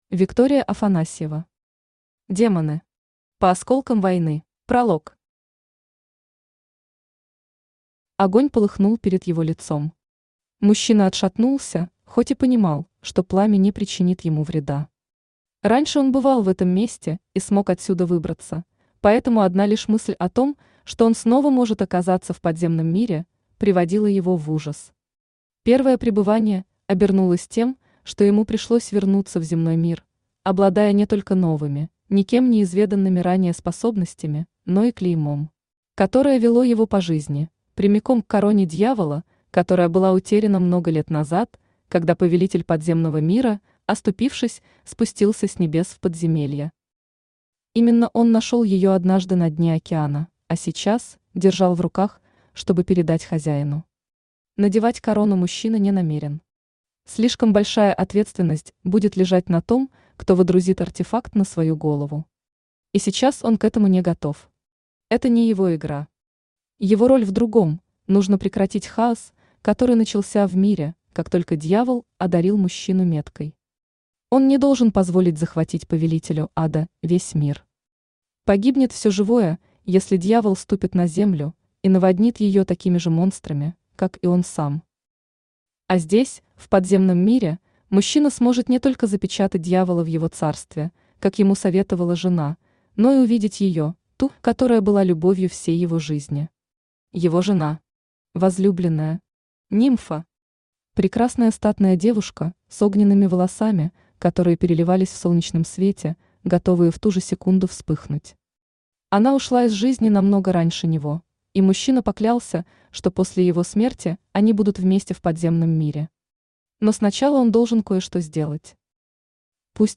Аудиокнига Демоны. По осколкам войны | Библиотека аудиокниг
По осколкам войны Автор Виктория Афанасьева Читает аудиокнигу Авточтец ЛитРес.